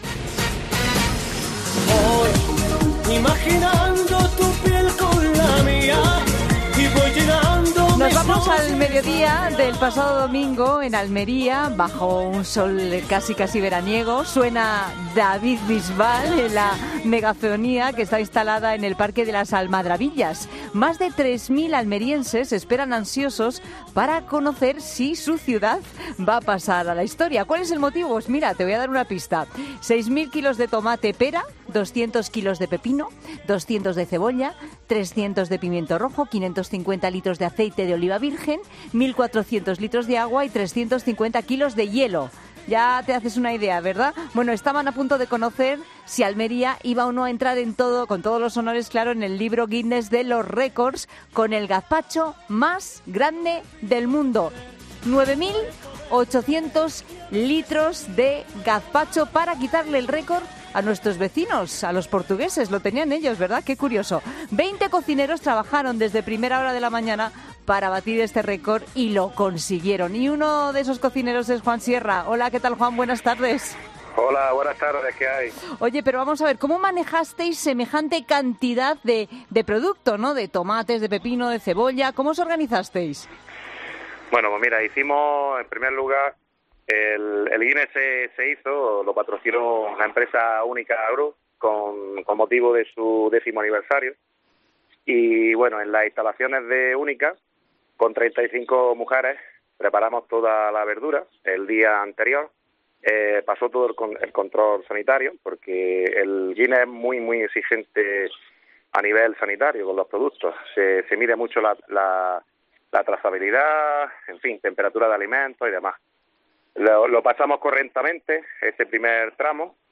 Un total de veinte cocineros trabajaron desde la madrugada para conseguirlo.